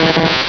Cri d'Élekid dans Pokémon Rubis et Saphir.
Cri_0239_RS.ogg